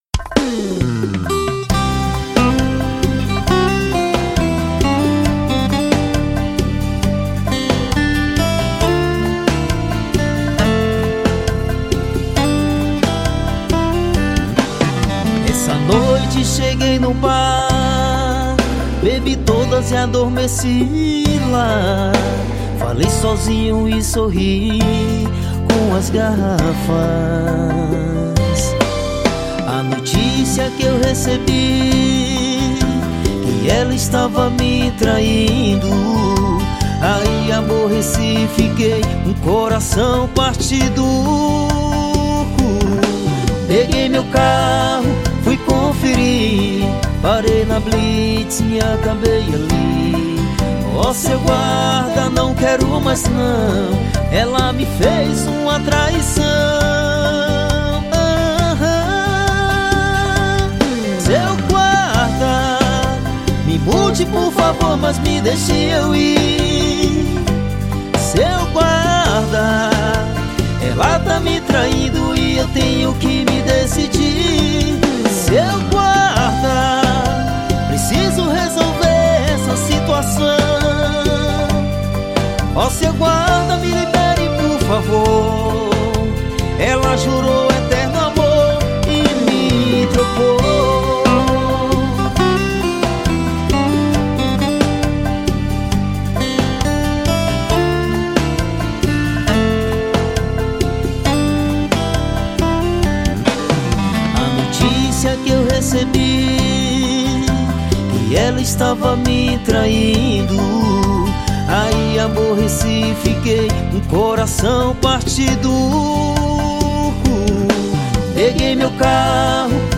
EstiloJingles / Spots